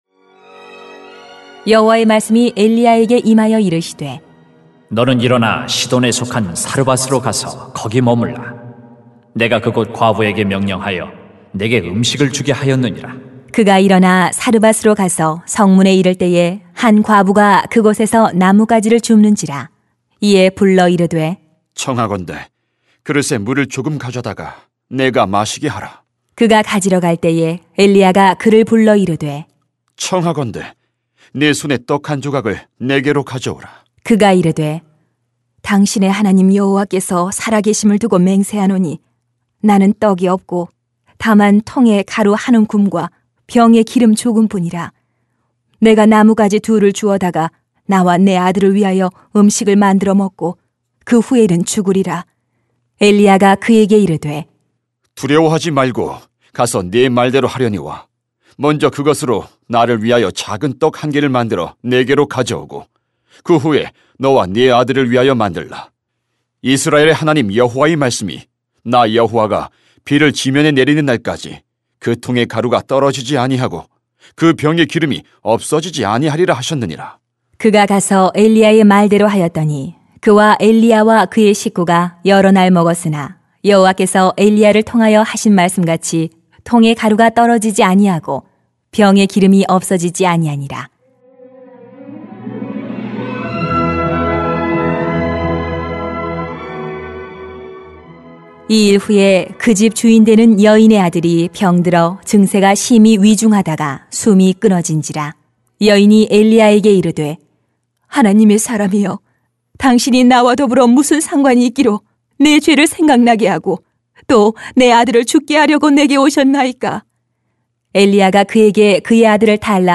[왕상 17:8-24] 하나님의 일을 하면 하나님이 돌보십니다 > 새벽기도회 | 전주제자교회